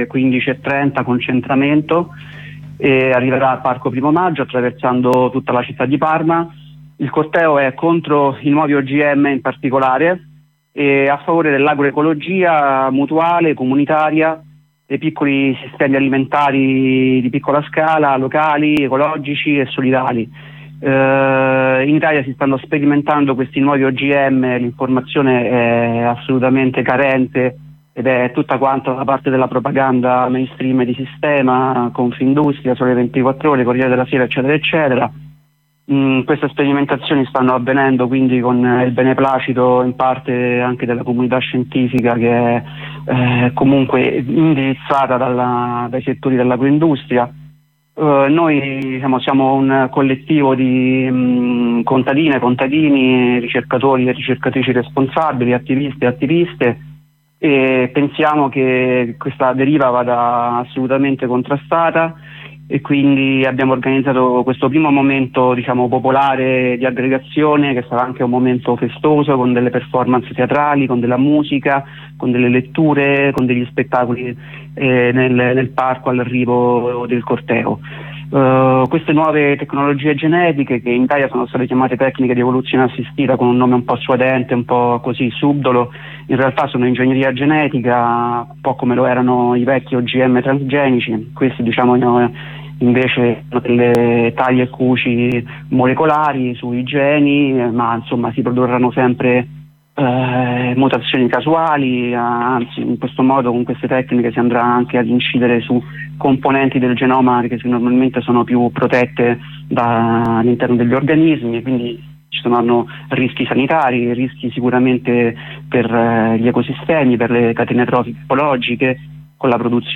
Ai nostri microfoni un attivista del coordinamento Cambiare il campo.